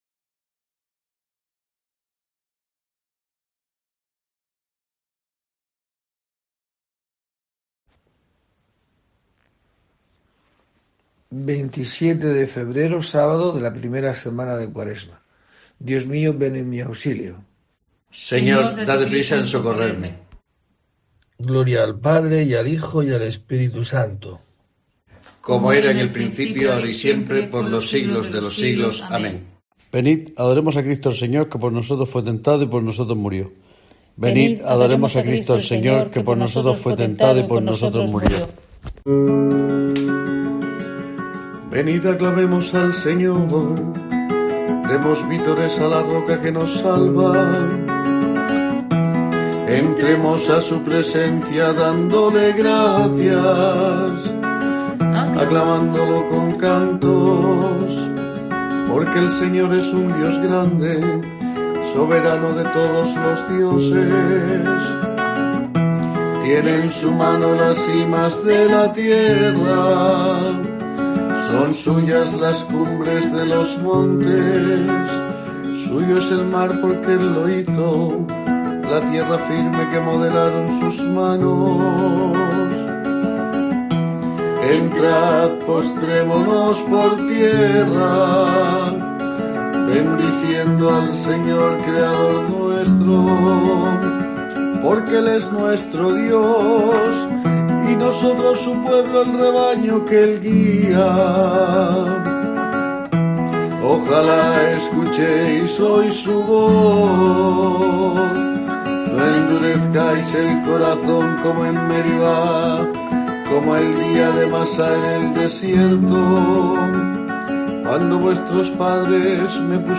27 de febrero: COPE te trae el rezo diario de los Laudes para acompañarte